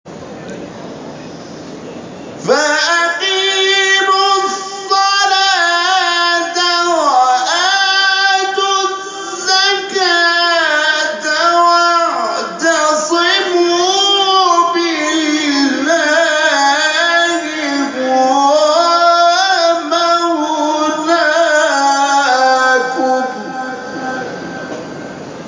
شبکه اجتماعی: مقاطع صوتی از قاریان ممتاز کشور را می‌شنوید.